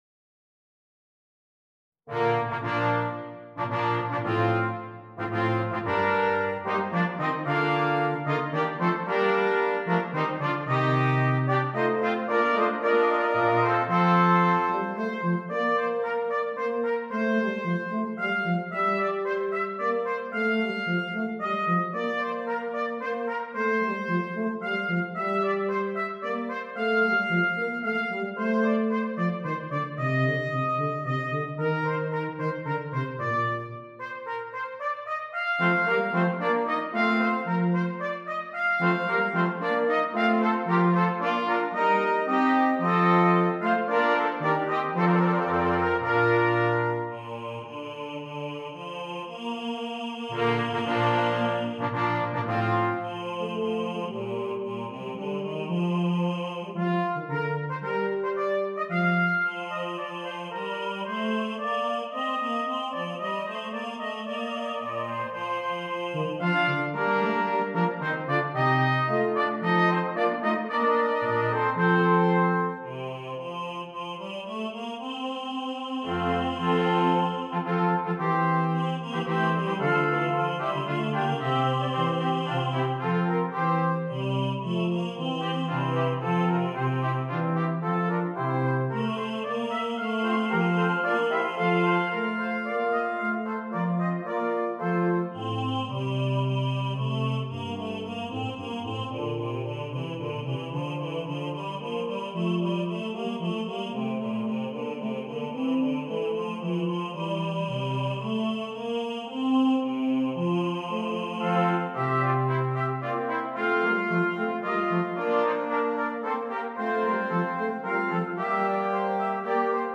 Brass Quintet and Baritone Voice